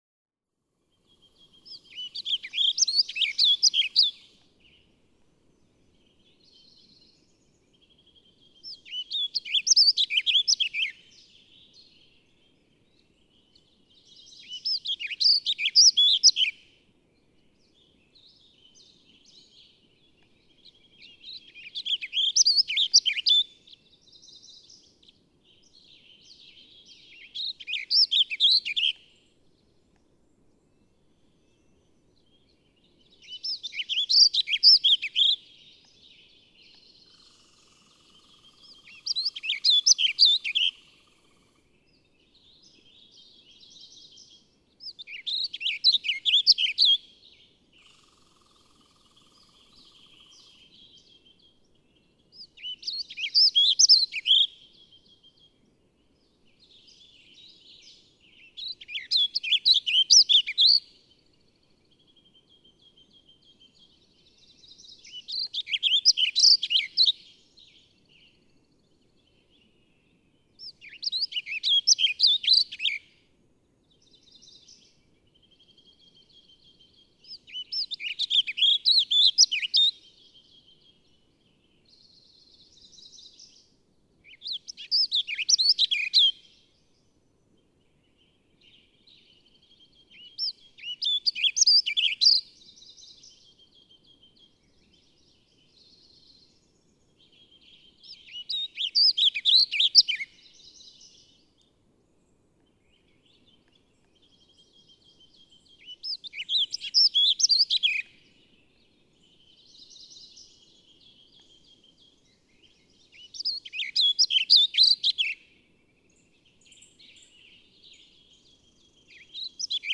Warbling vireo
♫580. The choppy songs of the western warbling vireo.
Two Ocean Lake, Grand Teton National Park, Wyoming.
580_Warbling_Vireo.mp3